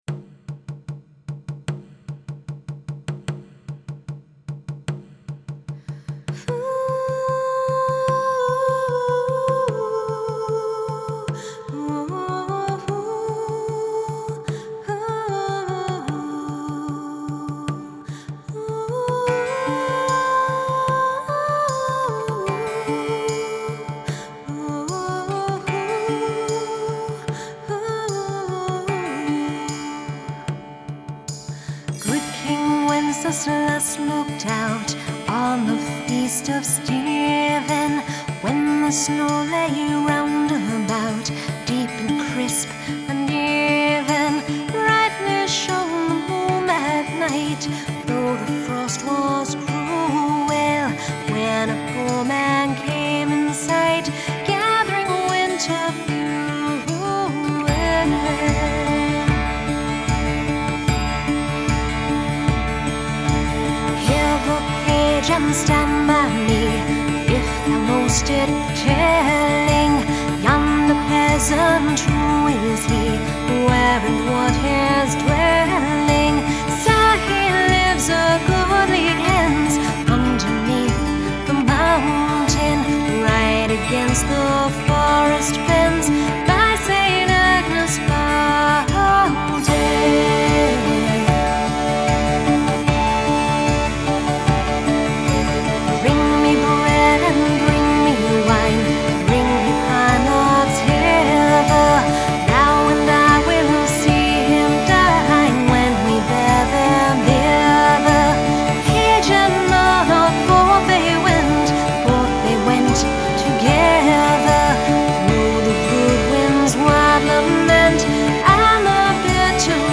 Жанр: фолк-рок
Genre: Folk, Rock